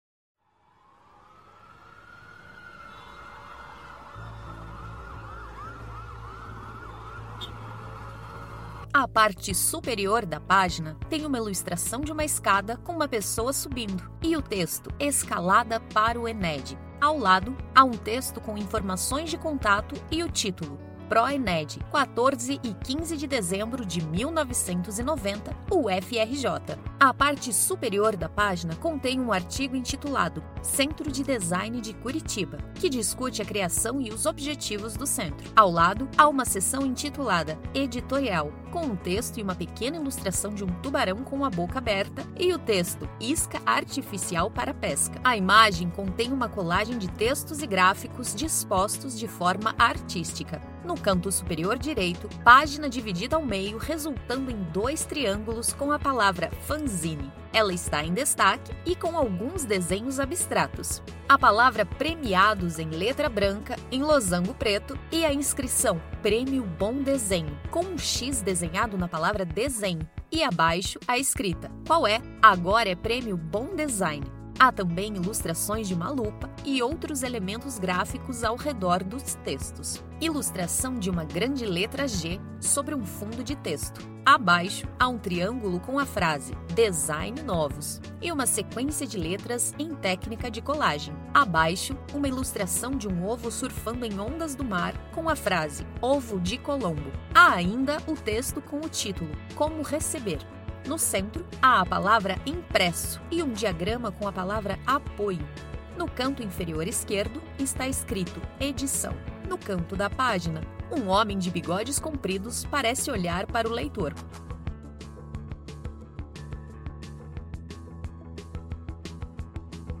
Audiodescrição do Fanzine n° 2